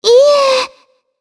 Shea-Vox-Deny_jp.wav